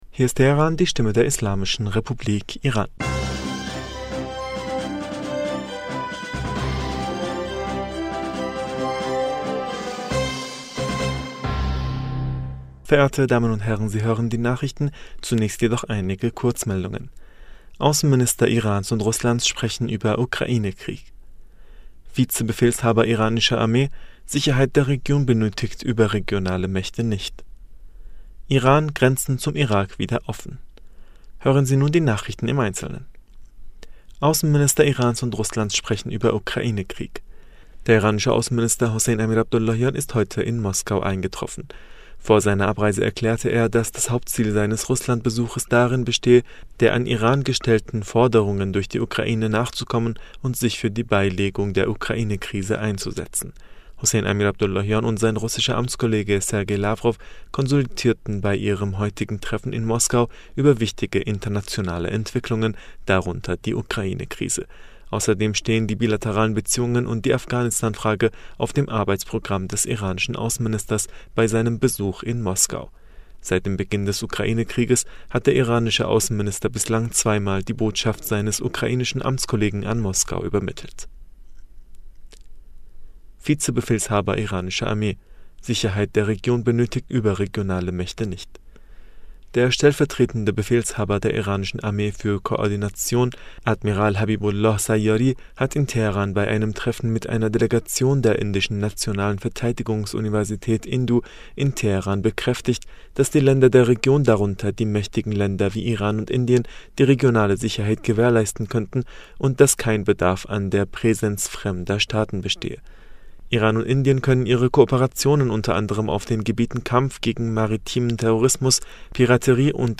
Nachrichten vom 31. August 2022